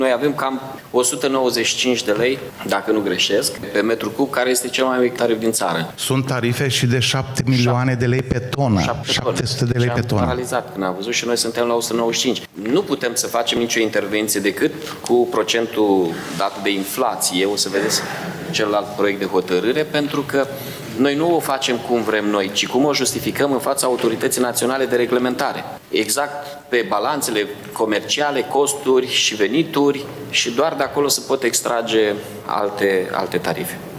Ieșenii urmează să plătească cu aproximativ 10% mai mult pentru salubritate, după ce tarifele majorate cu rata inflației pentru serviciile prestate de Salubris au primit undă verde în ședința de astăzi a Consiliului Local.